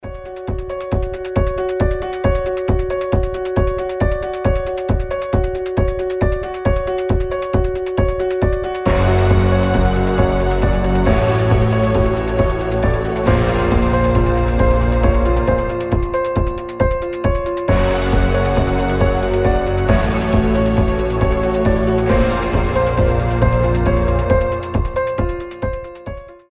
• Качество: 320, Stereo
без слов
пугающие
из фильмов
страшные
жуткие
тревога